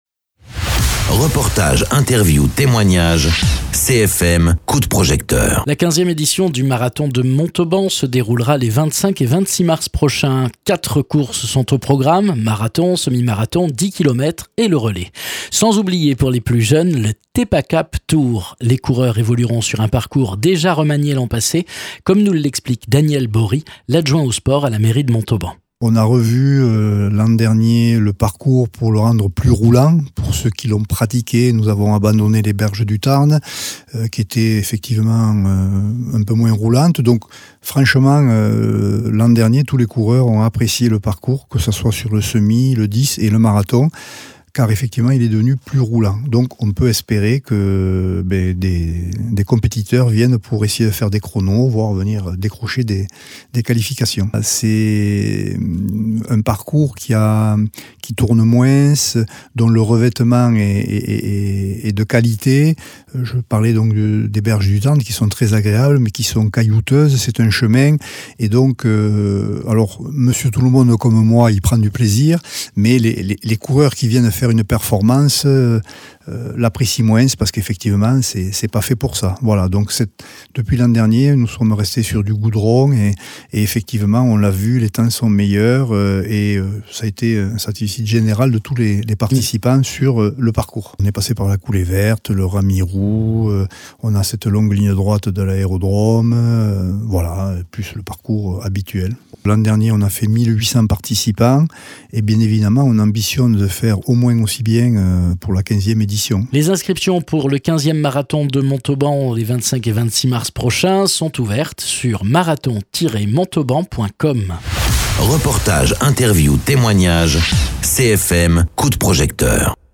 Interviews
Invité(s) : Daniel Bory adjoint au sport à la mairie de Montauban